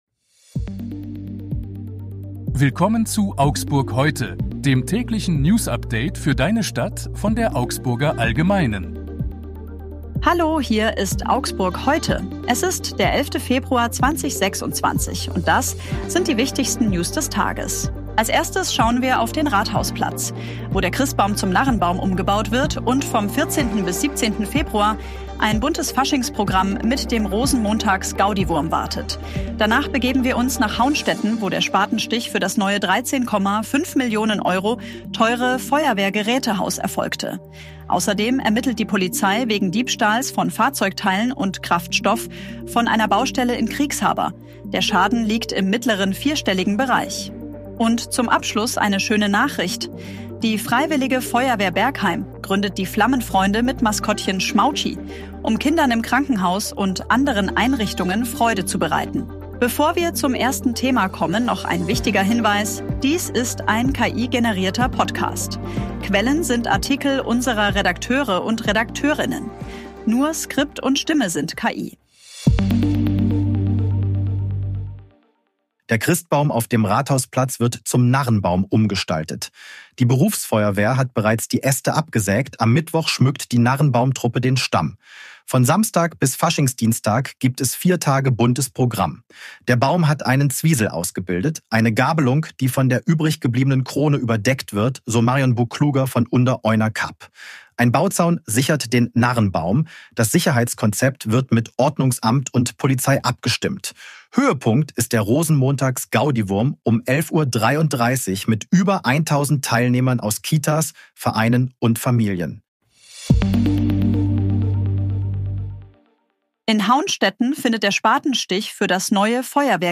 Hier ist das tägliche Newsupdate für deine Stadt.
Nur Skript und Stimme sind KI.